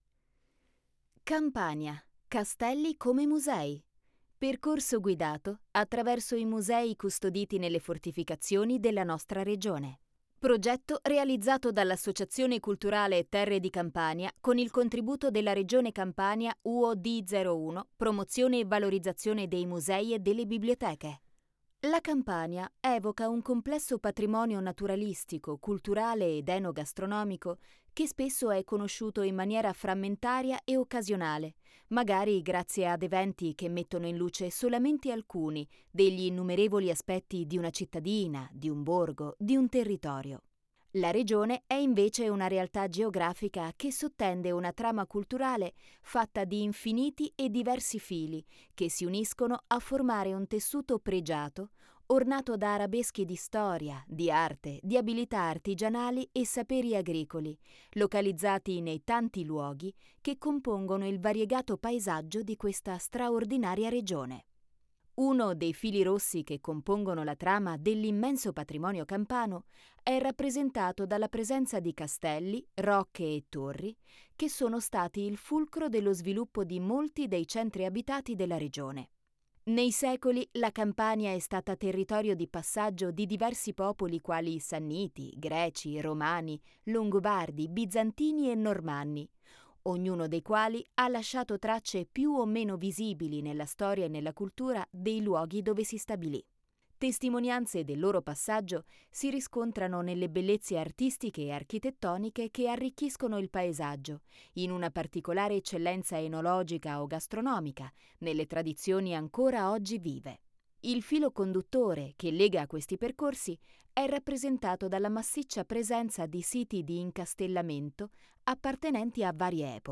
Audioguida-Castelli-Campani.mp3